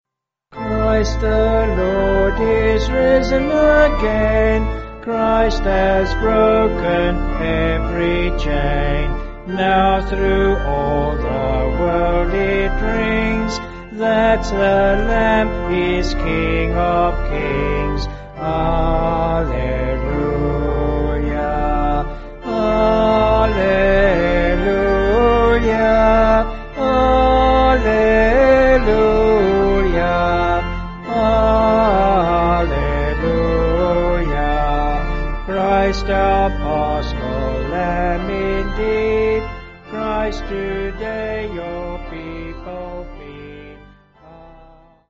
(BH)   3/Dm
Vocals and Organ